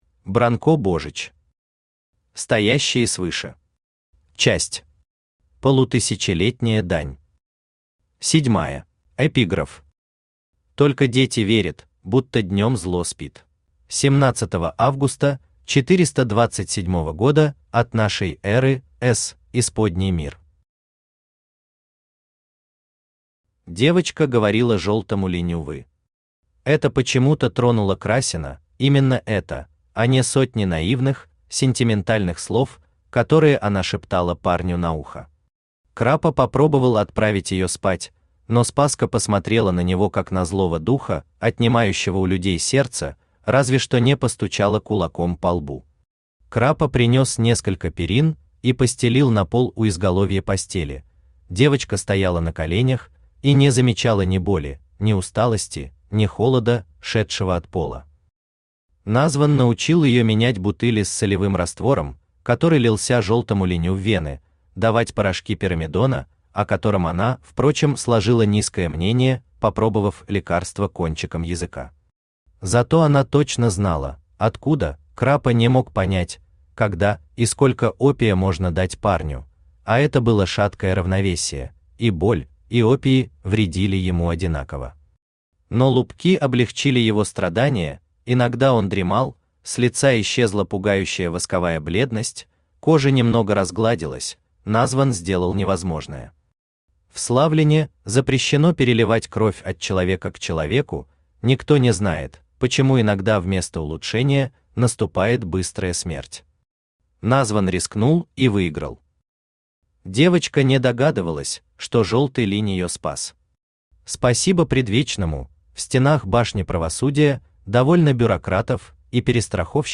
Аудиокнига Стоящие свыше. Часть VII. Полутысячелетняя дань | Библиотека аудиокниг
Полутысячелетняя дань Автор Бранко Божич Читает аудиокнигу Авточтец ЛитРес.